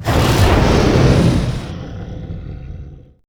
taunt1.wav